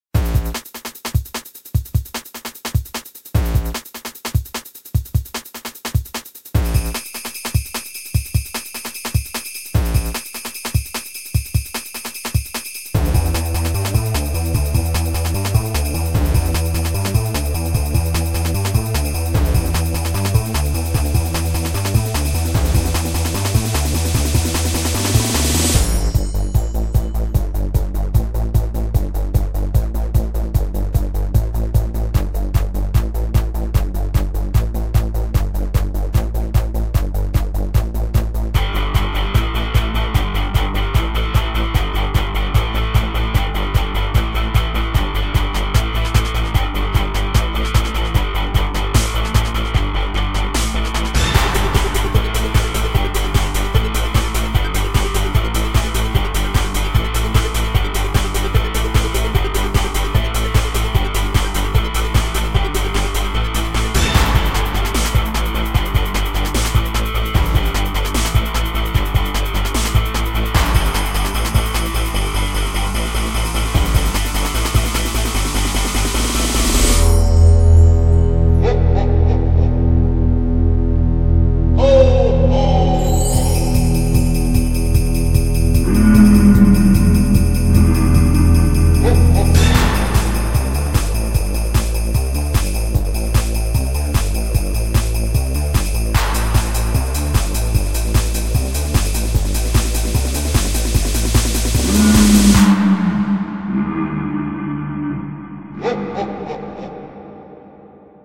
nice, but i still hate electronic music